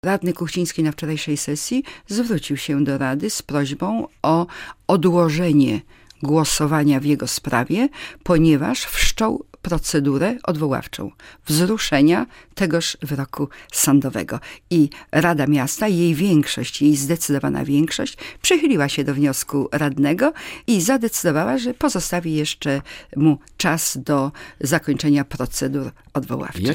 Przewodnicząca olsztyńskiej Rady Miasta Halina Ciunel, która była gościem Porannych Pytań Radia Olsztyn podkreśliła, że Rada ma 30 dni na zajęcie stanowiska w tej sprawie.